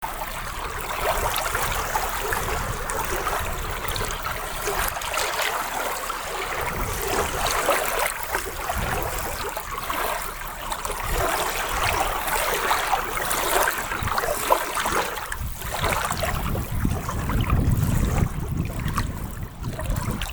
深山ダムの音
データ（MP3：481KB） 渚(ダム湖)の音